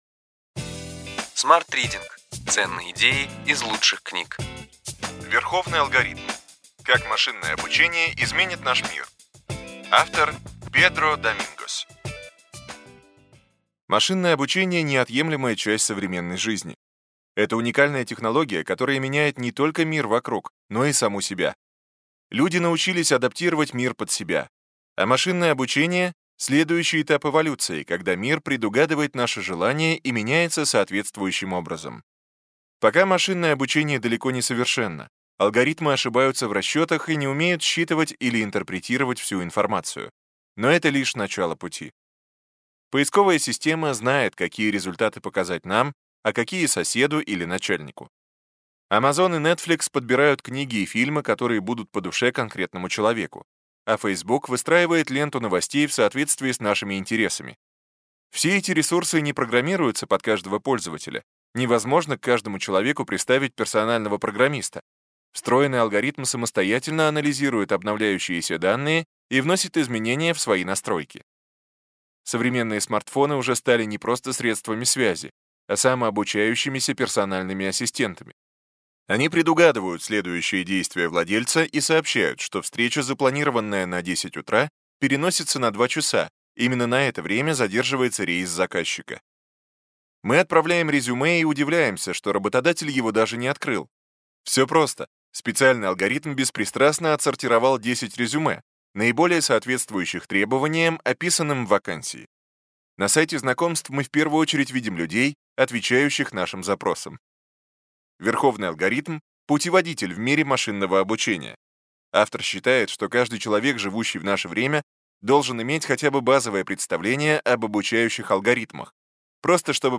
Аудиокнига Ключевые идеи книги: Верховный алгоритм. Как машинное обучение изменит наш мир.